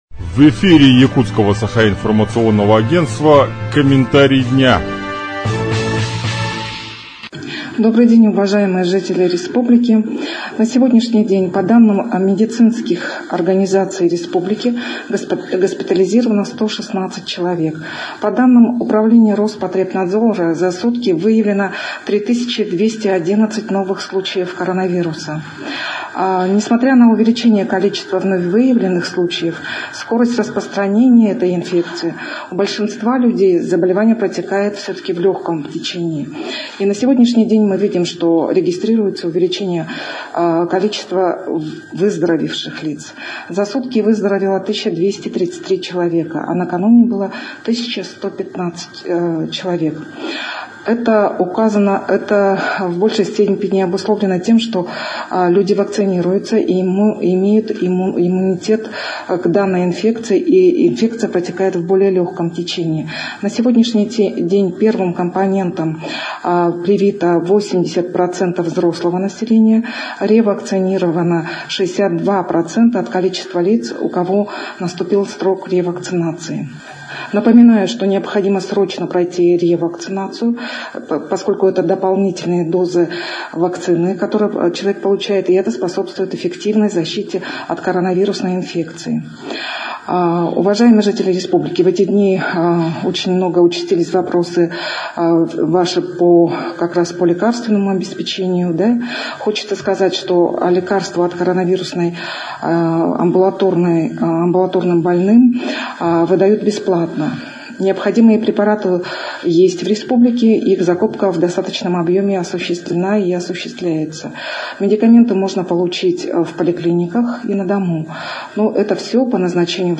Подробнее в аудиокомментарии  министра здравоохранения Якутии Лены Афанасьевой: